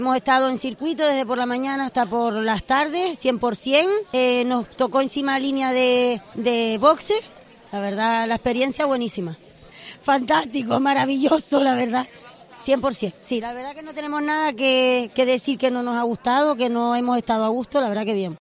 Aficionados disfrutando del GP de España de MotoGP